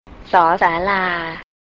สอ-สา-ลา
sor sar-lar
sand (high tone)